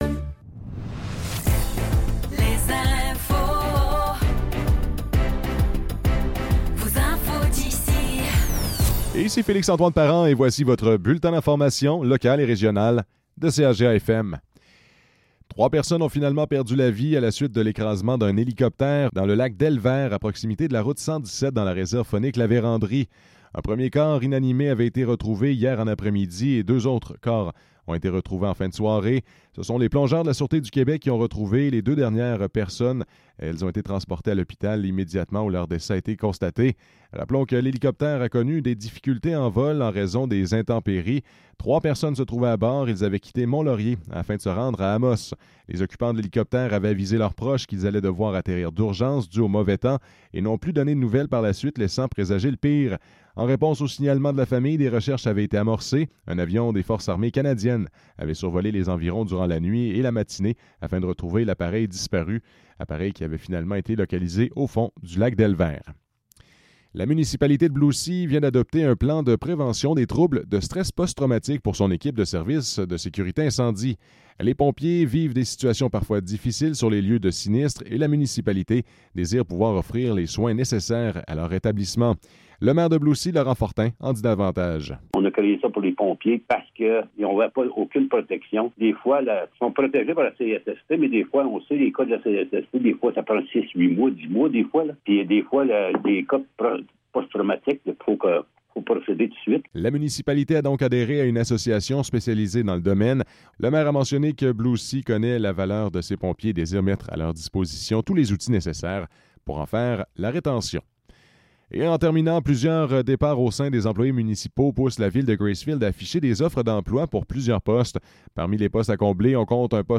Nouvelles locales - 20 Août 2024 - 10 h